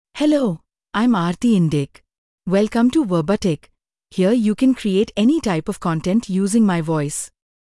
Aarti Indic — Female English (India) AI Voice | TTS, Voice Cloning & Video | Verbatik AI
Aarti Indic is a female AI voice for English (India).
Voice: Aarti IndicGender: FemaleLanguage: English (India)ID: aarti-indic-en-in
Voice sample
Listen to Aarti Indic's female English voice.
Aarti Indic delivers clear pronunciation with authentic India English intonation, making your content sound professionally produced.